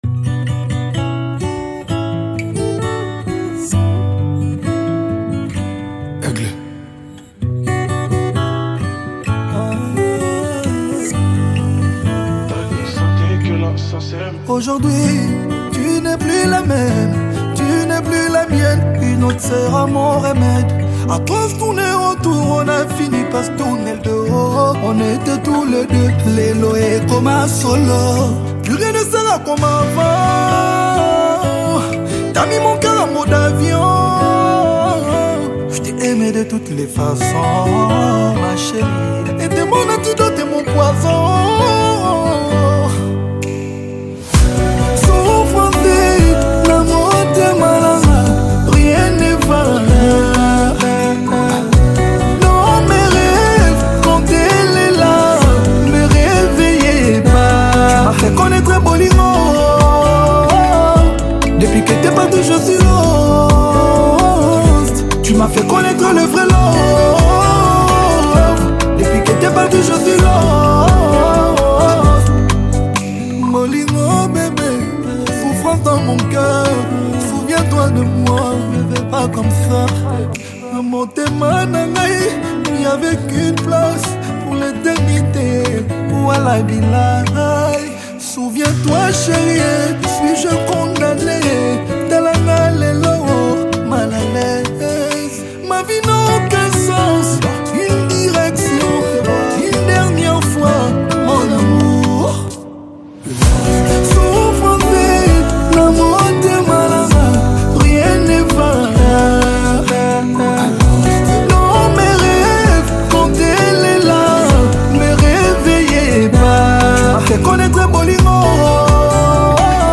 Highly gifted vocalist